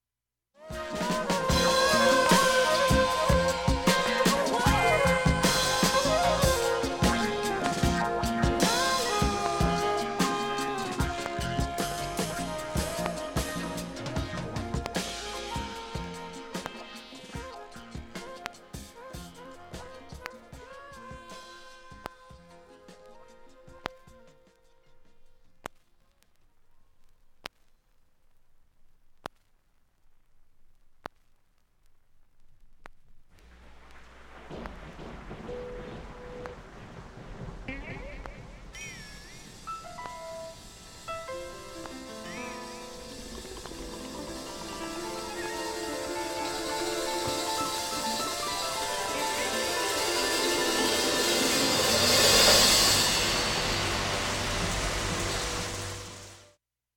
盤面きれいで音質良好全曲試聴済み。
B-3始めにかすかなプツが２３回出ます。